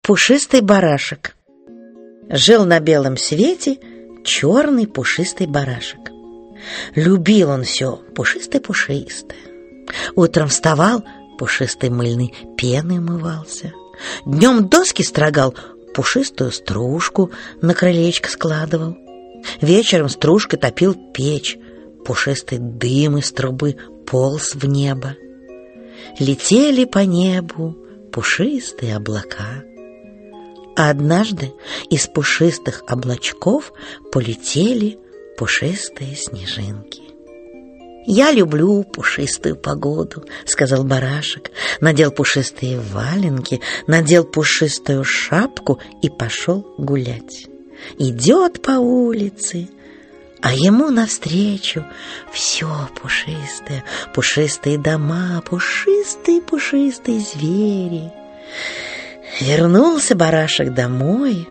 Аудиокнига 100 сказок-малюток | Библиотека аудиокниг
Aудиокнига 100 сказок-малюток Автор Геннадий Цыферов Читает аудиокнигу Александр Леньков.